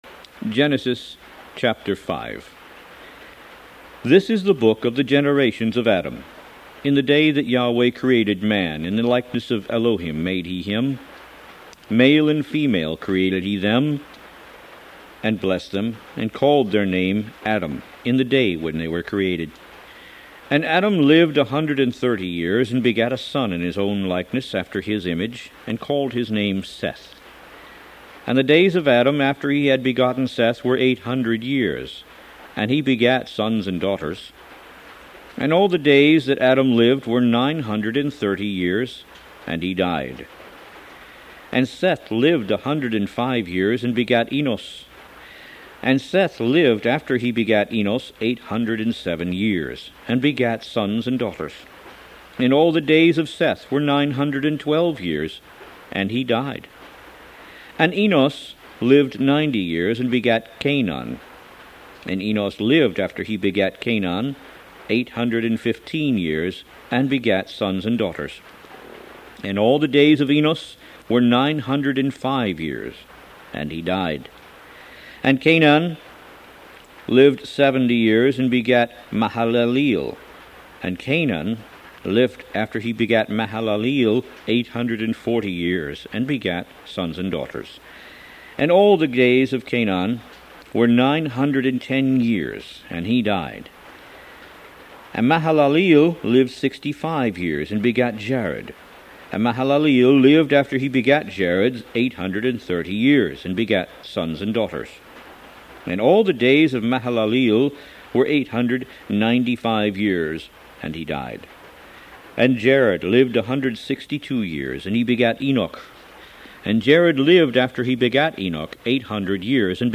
Root > BOOKS > Biblical (Books) > Audio Bibles > Tanakh - Jewish Bible - Audiobook > 01 Genesis